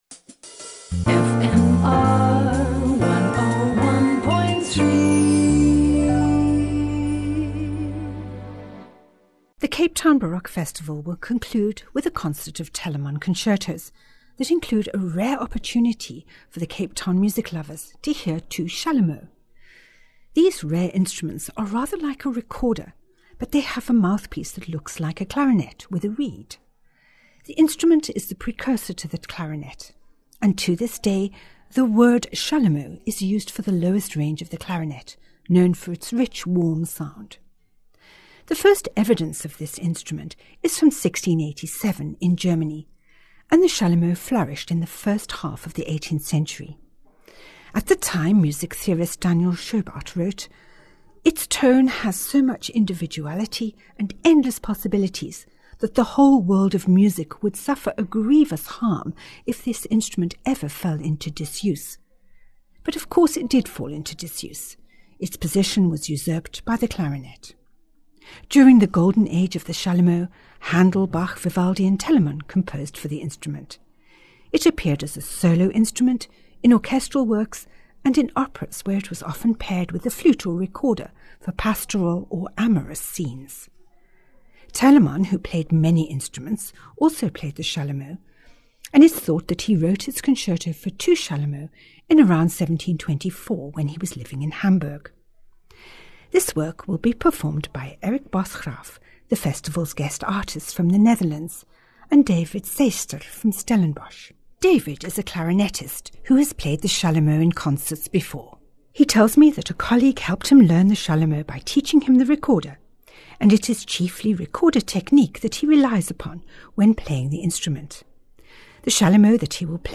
II. Allegro Artist